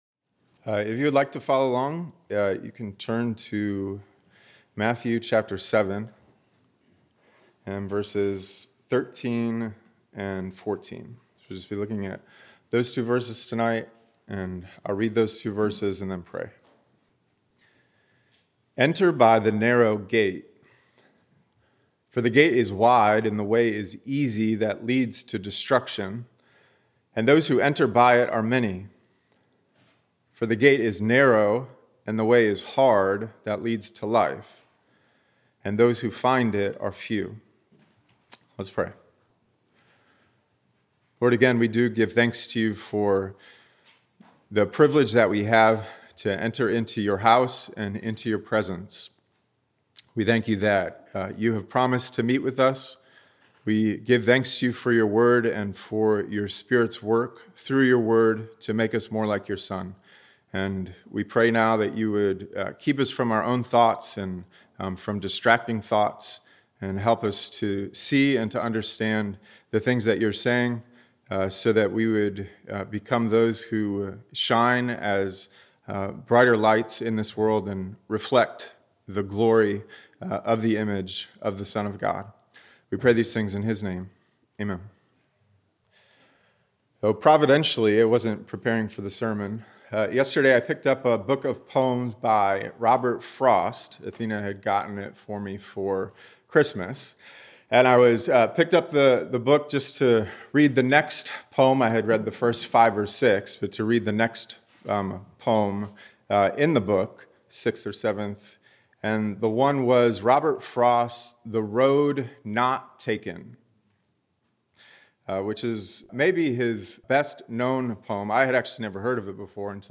Sunday Worship 11 AM & 6 PM
Message